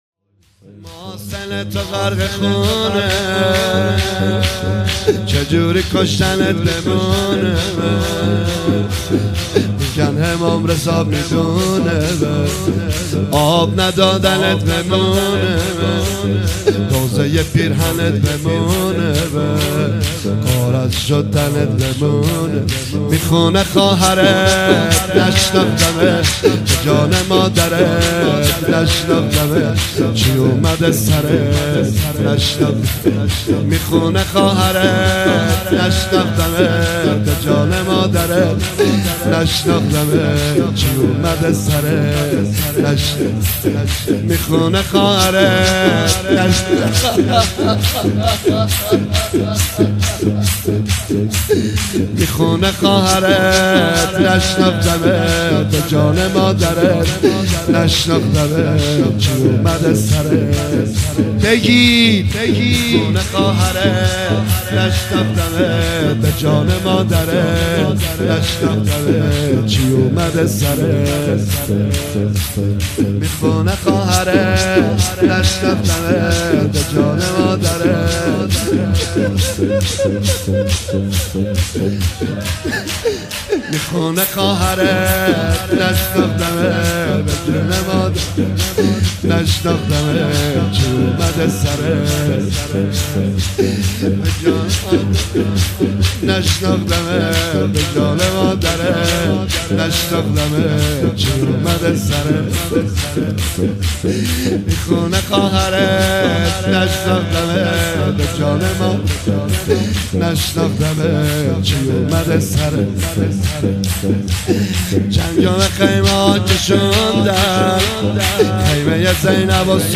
گلچین مداحی شب دهم (عاشورای حسینی) محرم